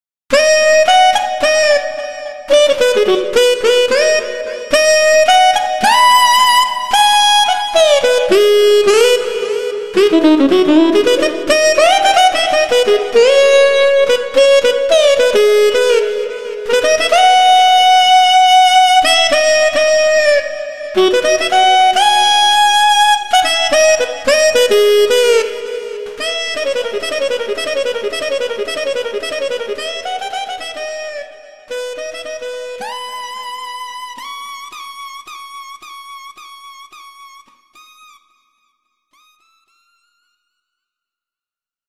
HALion6 : sax
Noisy Tenor Sax